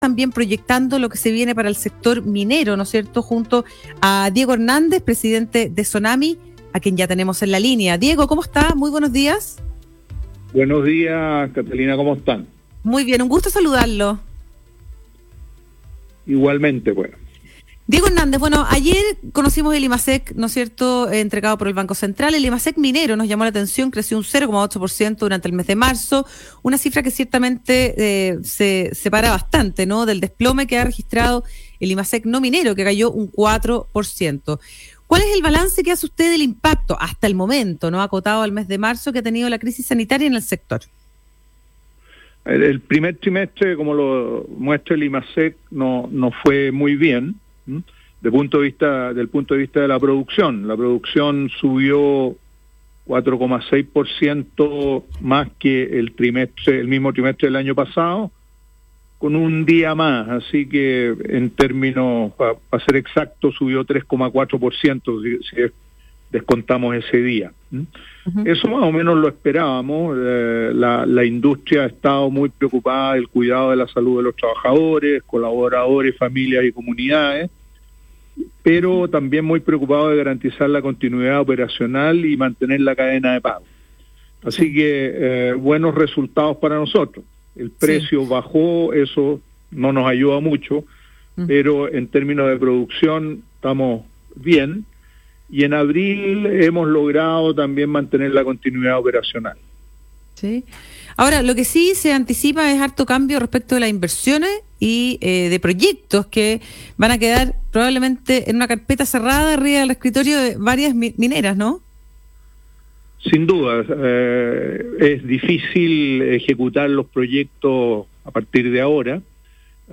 Entrevista Programa "Más que Números" - Radio Infinita - 5 de Mayo 2020 - SONAMI :: Sociedad Nacional de Minería - Chile